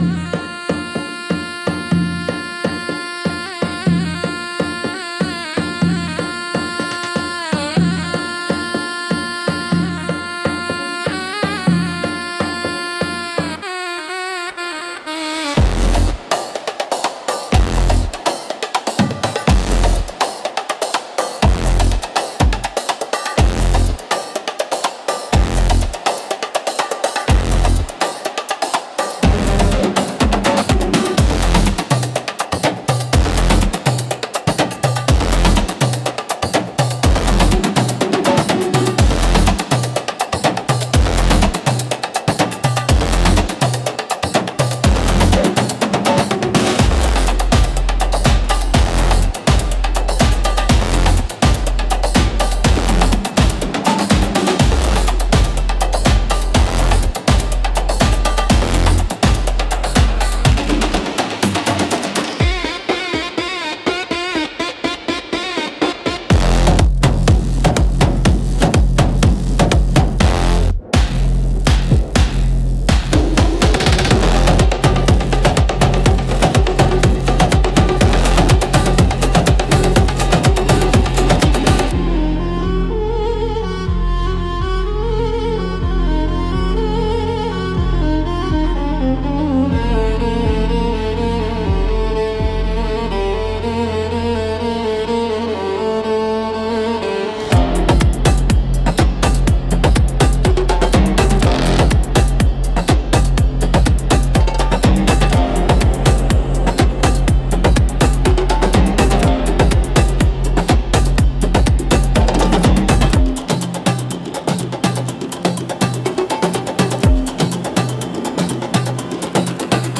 Instrumental - Crimson Mirage 4.23